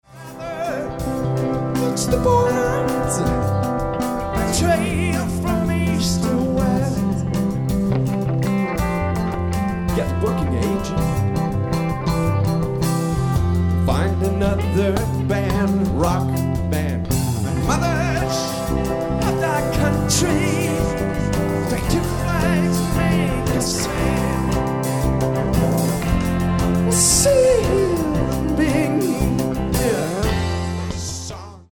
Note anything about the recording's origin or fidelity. at Ultrasound Showbar in Toronto, Canada